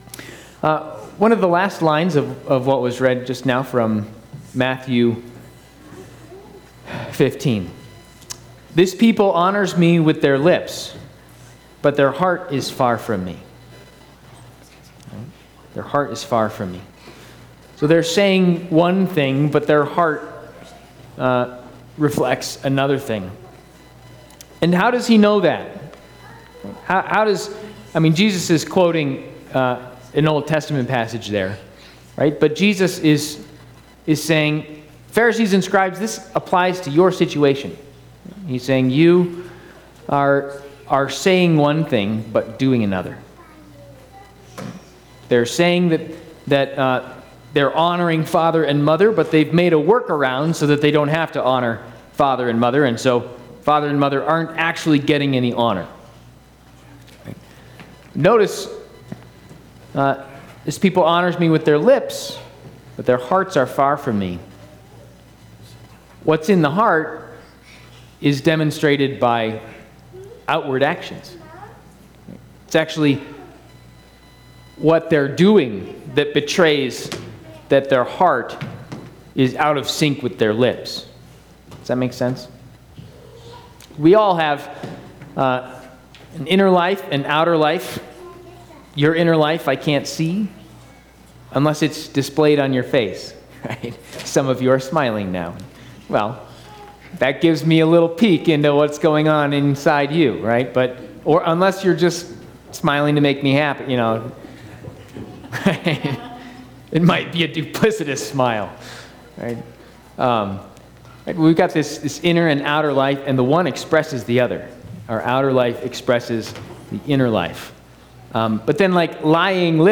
Advent Worship